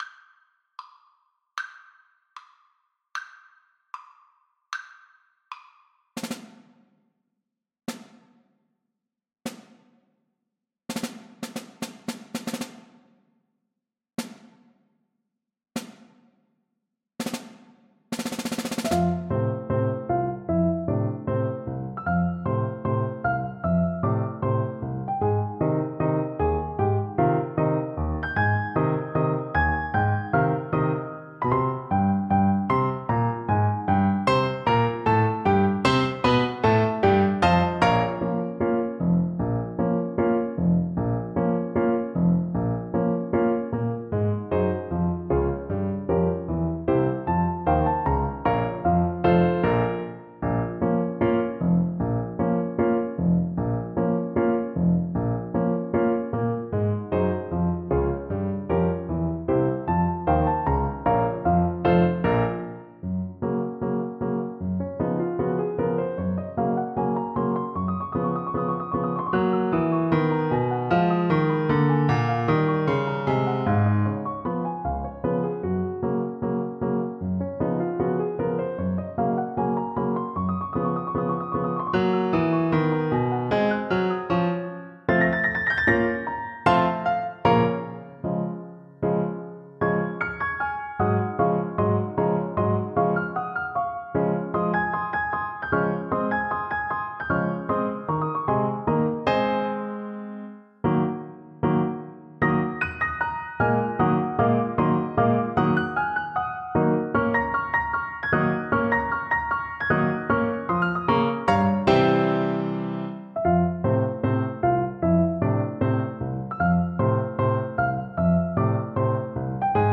Alto Saxophone version
Alto Saxophone
6/8 (View more 6/8 Music)
Ab4-F6
Allegro .=112 (View more music marked Allegro)
Classical (View more Classical Saxophone Music)